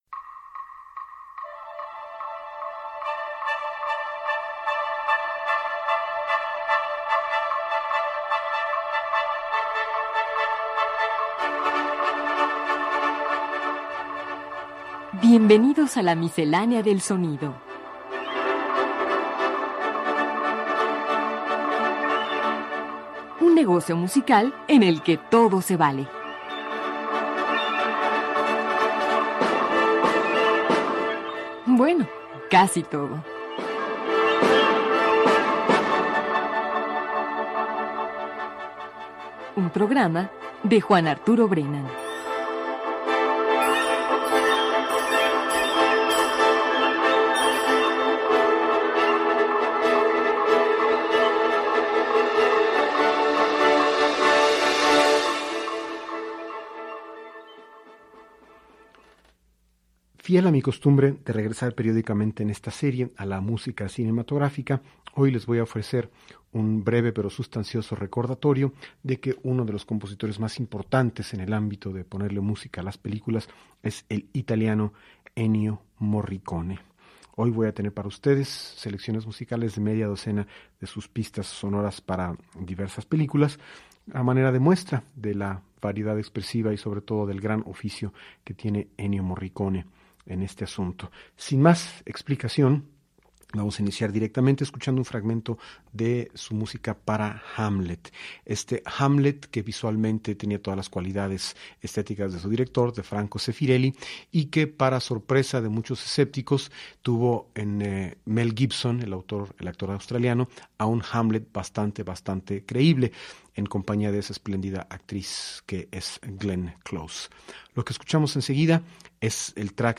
Escucha las pistas sonoras de varias películas